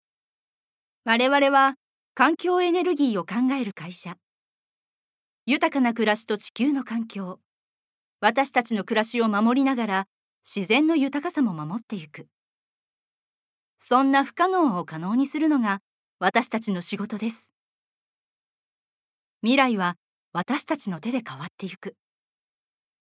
Voice Sample
ナレーション３